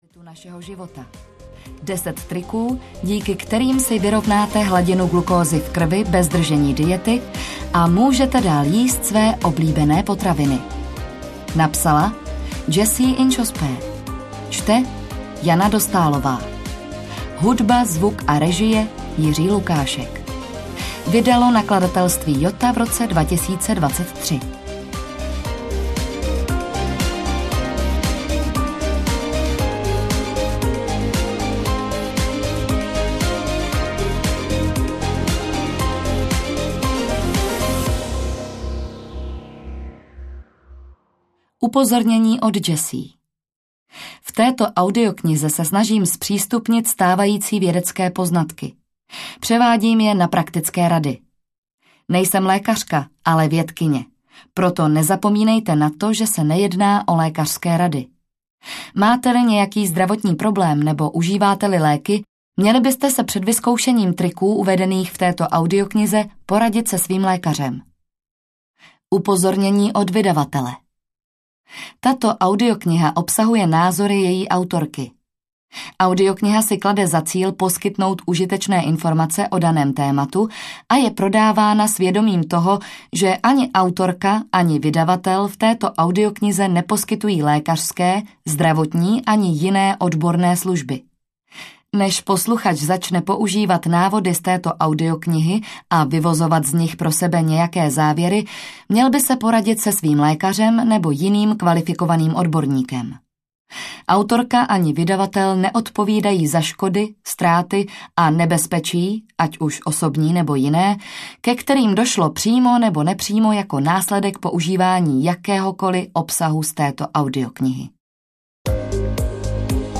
Pravda o cukru audiokniha
Ukázka z knihy